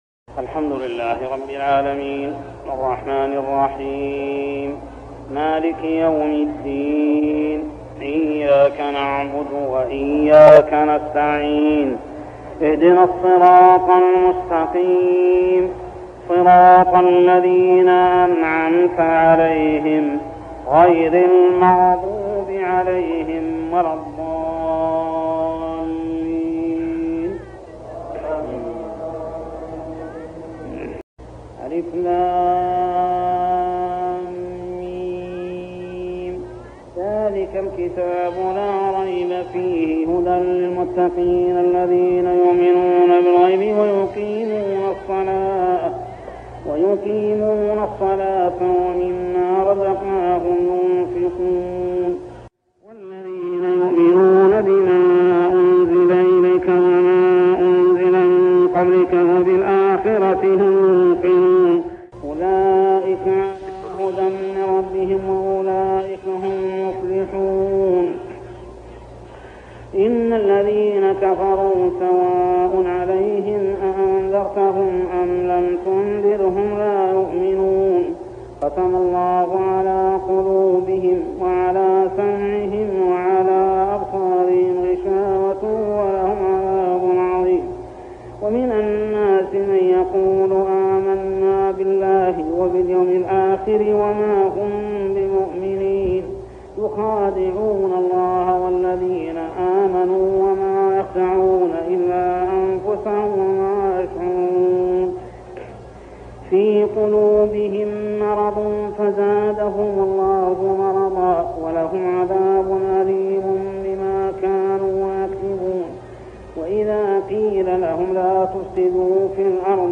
صلاة التراويح عام 1403هـ سورتي الفاتحة كاملة و البقرة 1-74 | Tarawih prayer Surah Al-Fatihah and Al-Baqarah > تراويح الحرم المكي عام 1403 🕋 > التراويح - تلاوات الحرمين